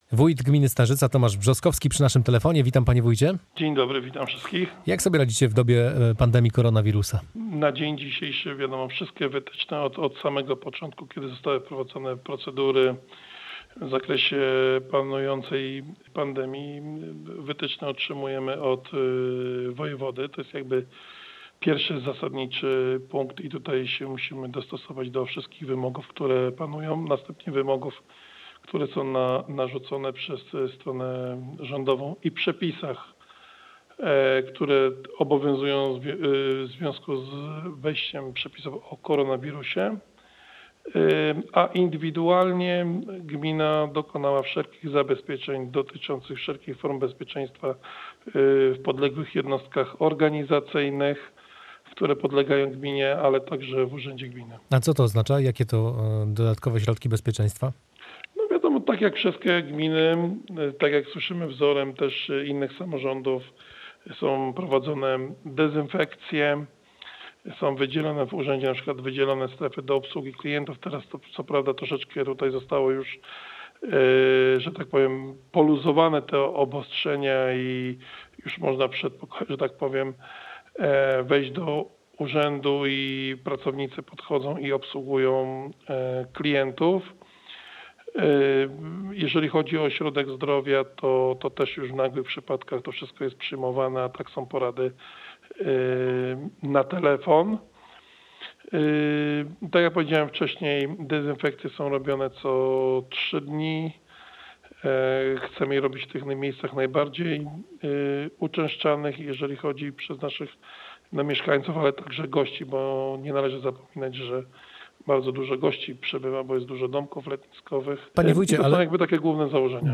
Następnie nasz reporter zadzwonił do wójta Dziemian Leszka Pobłockiego.